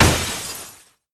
snd_mage_shield_break.ogg